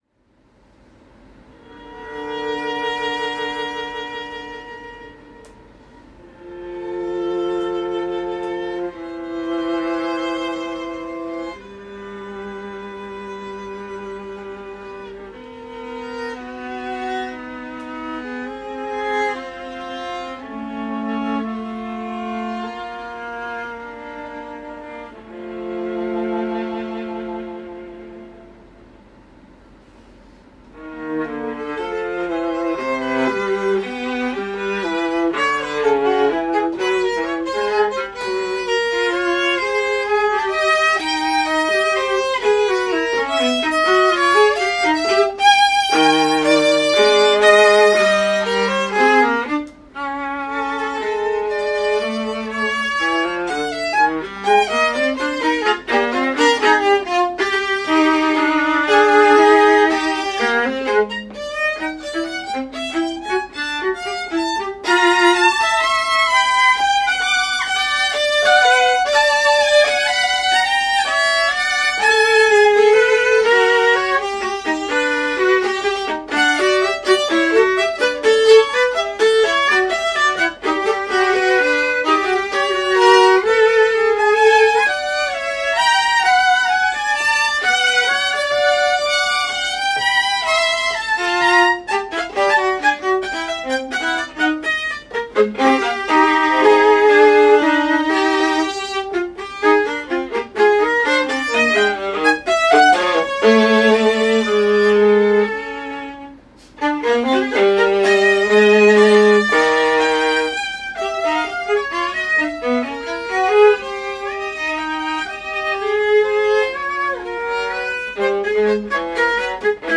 for viola duo
Rehearsal recordings of the three duets can be heard at the following links:
Viola_Duets2.mp3